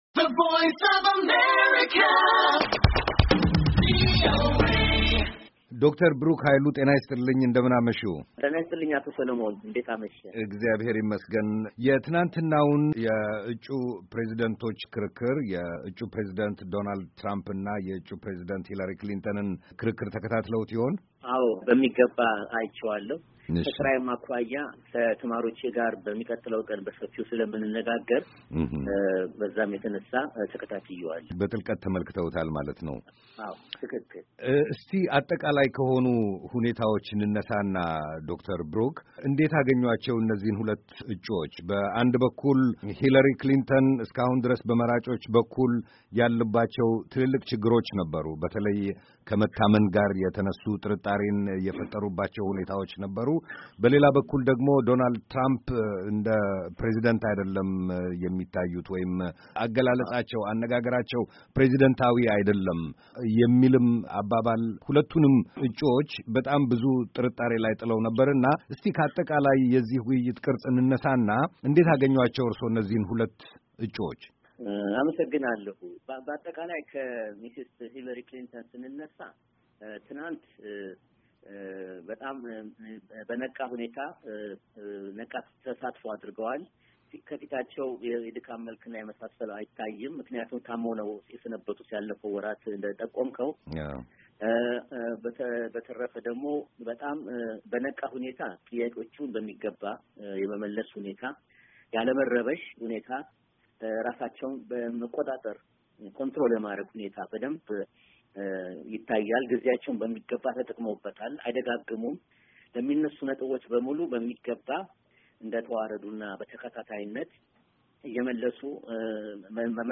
US Presidential Debate - interview